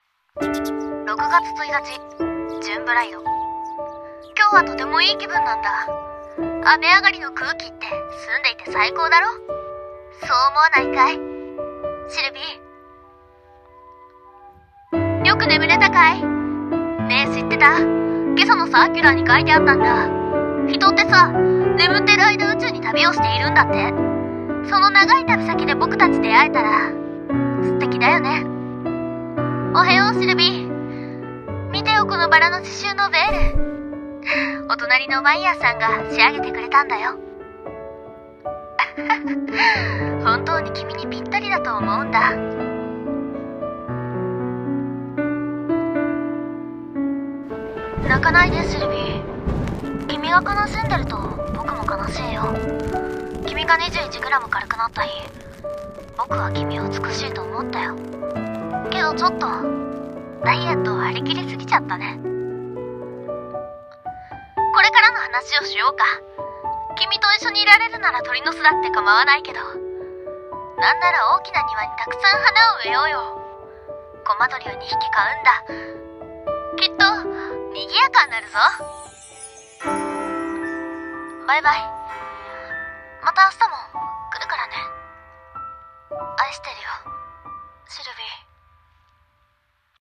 CM風声劇「シルヴィ